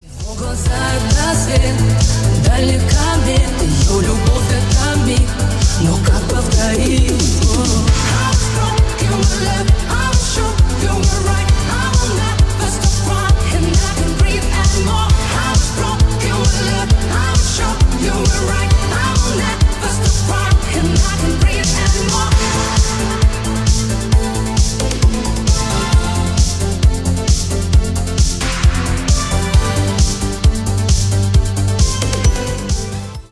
бесплатный рингтон в виде самого яркого фрагмента из песни
Ремикс
клубные